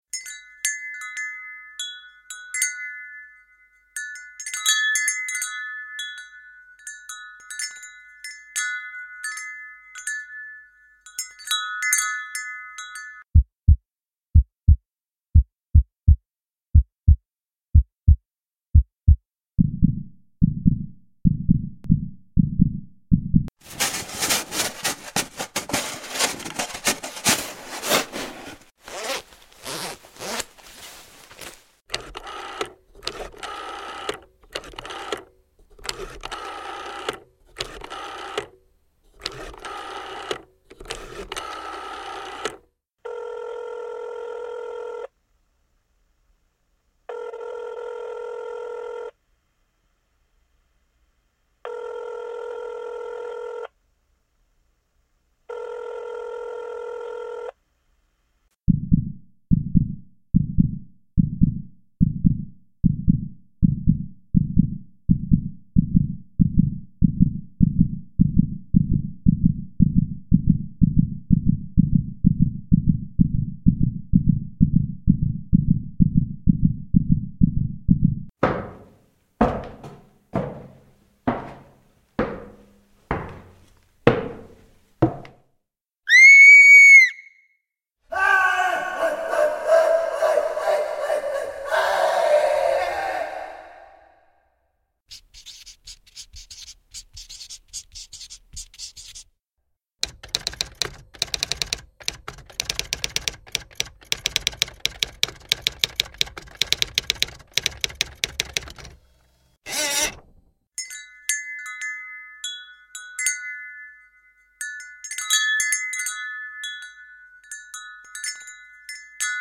sound-effects.mp3